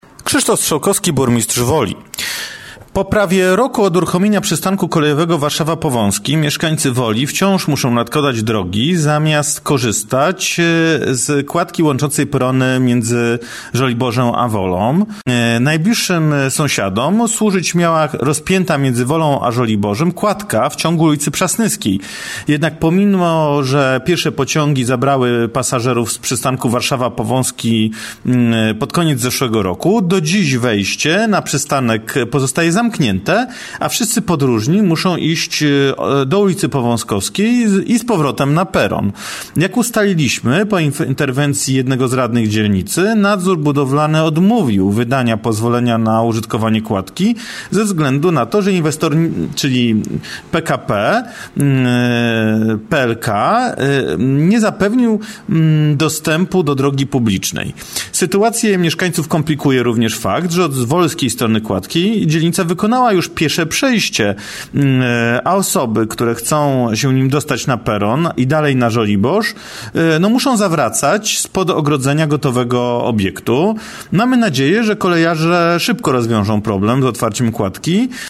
Krzysztof Strzałkowski, burmistrz Woli: